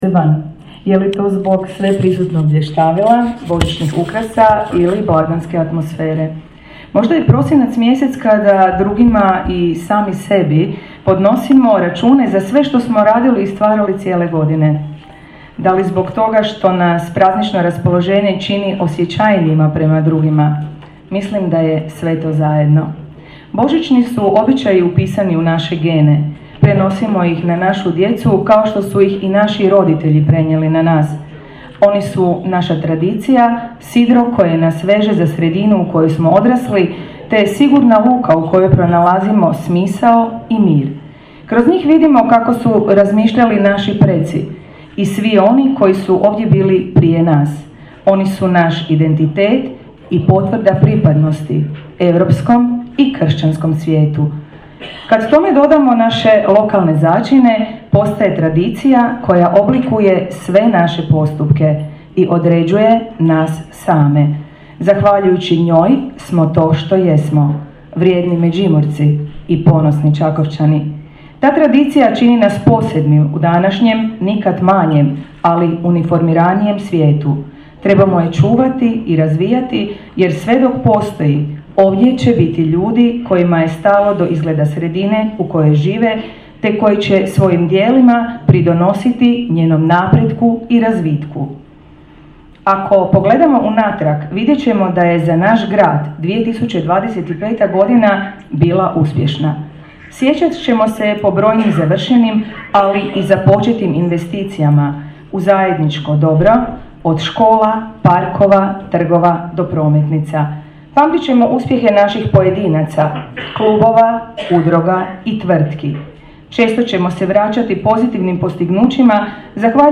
Božićni prijem gradonačelnice Grada Čakovca Ljerke Cividini